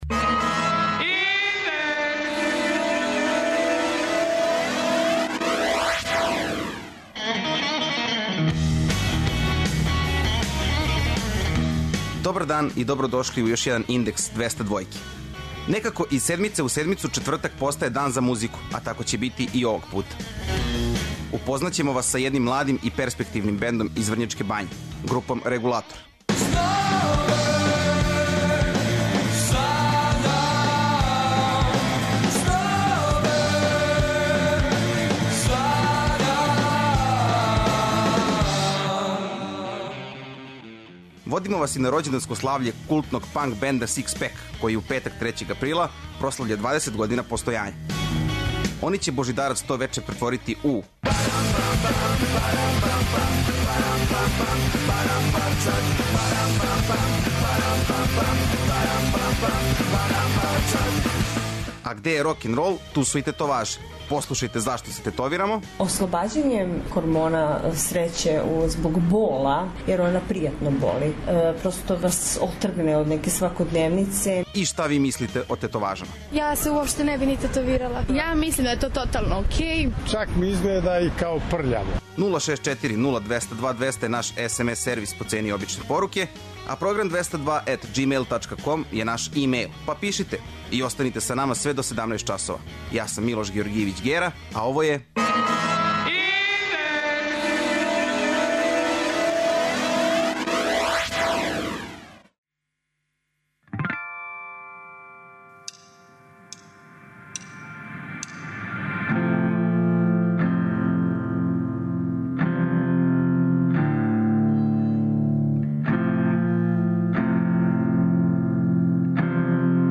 ''Индекс 202'' је динамична студентска емисија коју реализују најмлађи новинари Двестадвојке.
Поред тога, ослушните и једну занимљиву репортажу о тетоважама.